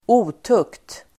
Ladda ner uttalet
Uttal: [²'o:tuk:t]